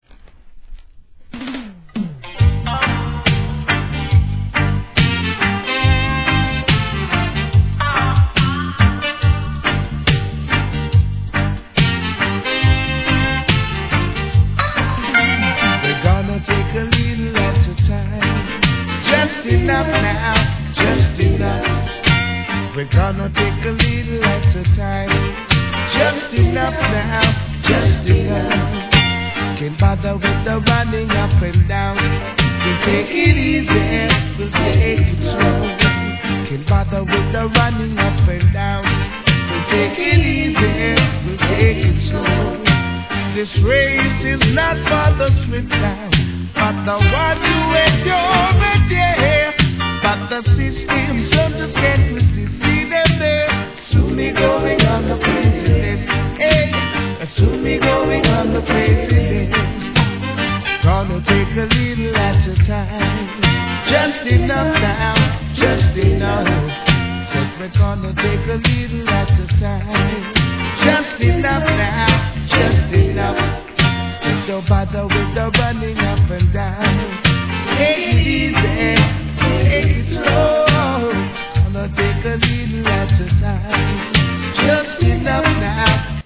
REGGAE
1984年、全曲後半DUB接続Show Caseスタイルで収録!!